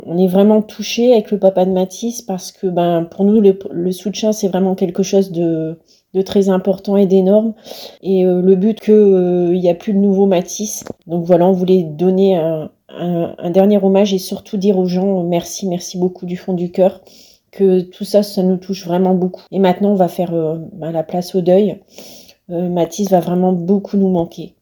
Hier Mardi , près d’un millier de ses proches , amis mais aussi des anonymes réunis pour un dernier “au revoir” .